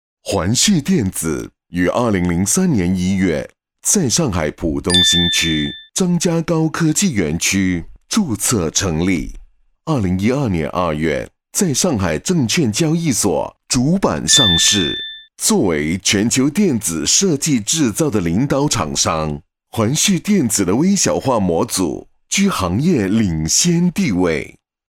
环旭电子DEMO - 粤语男1号 - 光影嘉乐旗舰店